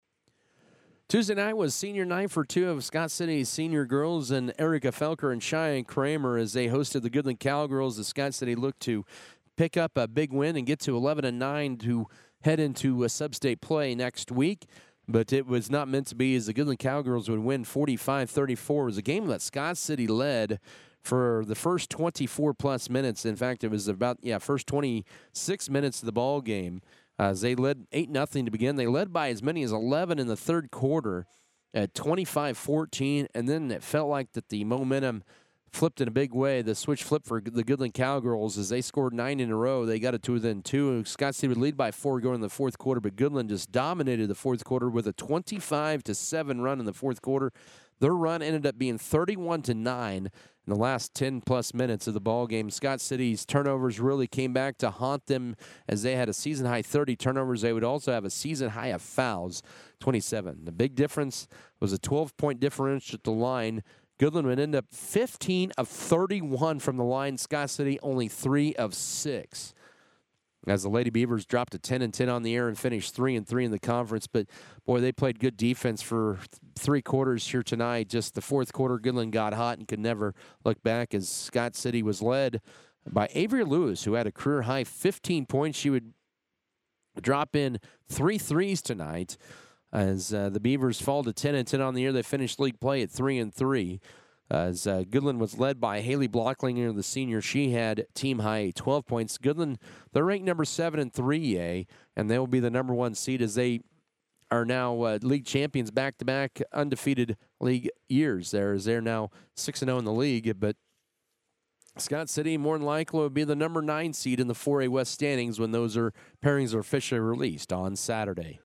Girls Audio Recap